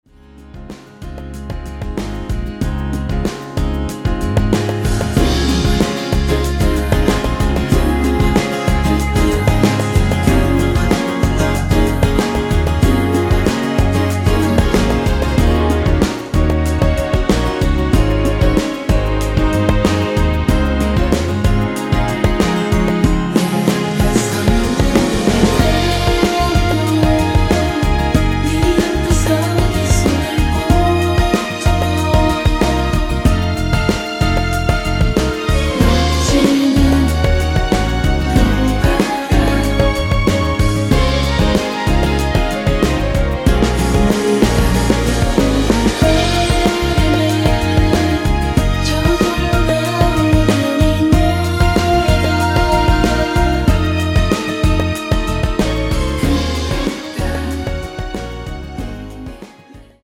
원키 코러스포함된 MR 입니다.(미리듣기 참조)
Db
앞부분30초, 뒷부분30초씩 편집해서 올려 드리고 있습니다.
중간에 음이 끈어지고 다시 나오는 이유는